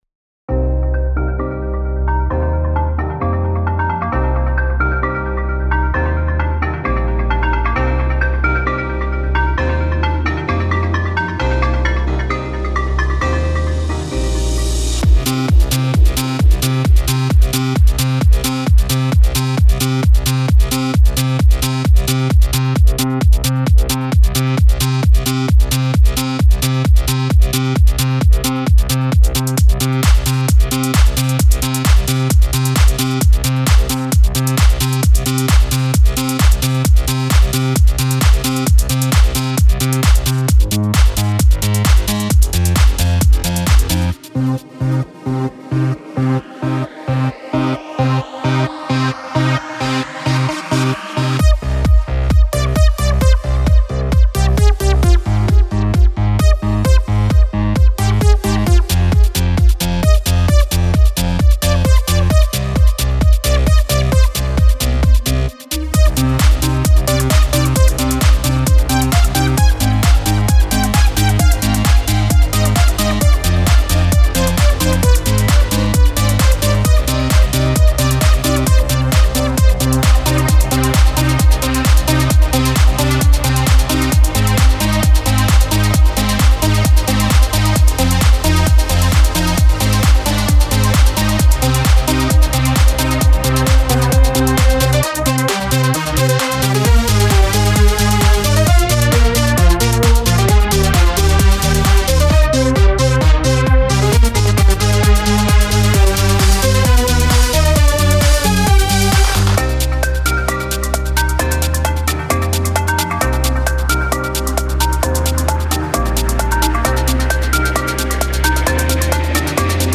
Here's a dance remix of the ingame music.